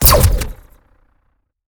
LaserFire.wav